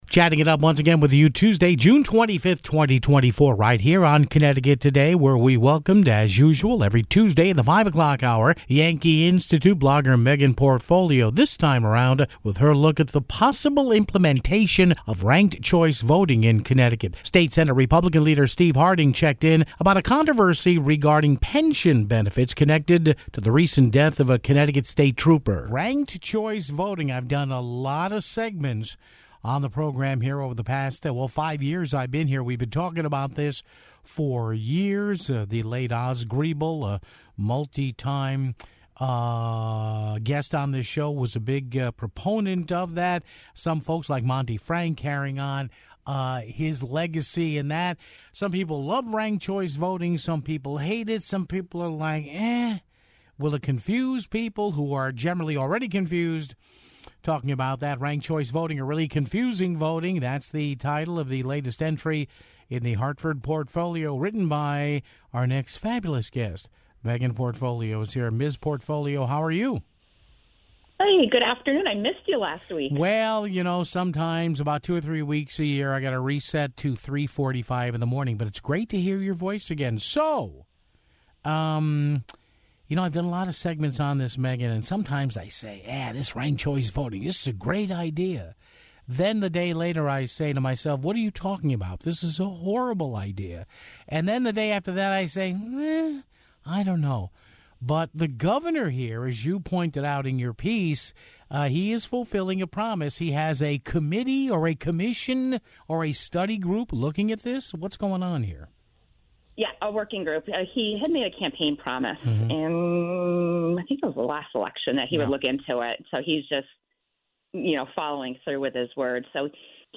State Senate Republican leader Steve Harding talked about a controversy regarding pension benefits connected to the recent death of a Connecticut State Trooper (10:14)